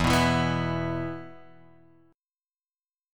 E5 chord {0 2 2 x 0 0} chord